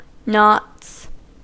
Ääntäminen
Ääntäminen US : IPA : [nɑts] Tuntematon aksentti: IPA : /ˈnɒts/ Haettu sana löytyi näillä lähdekielillä: englanti Käännöksiä ei löytynyt valitulle kohdekielelle.